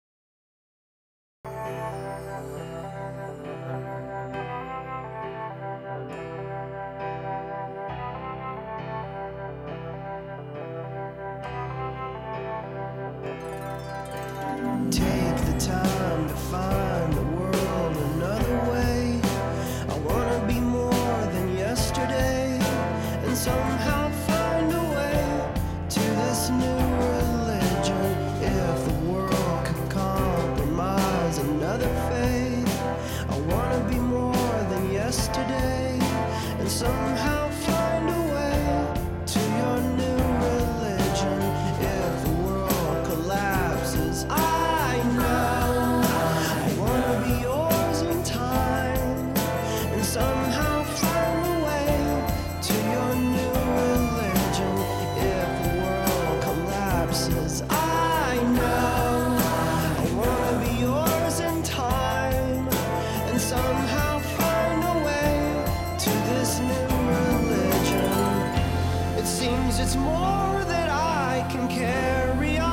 The latest package I have tried is called recordmydesktop and as far as I know its the only one that records sound as well as video. Here's a clip featuring bongo, a great music player for emacs.
To get sound working you need set alsa to record from the `wave' device of your sound card (actually there are plenty of other ways but this worked for me.)
polyphonic_emacs.ogg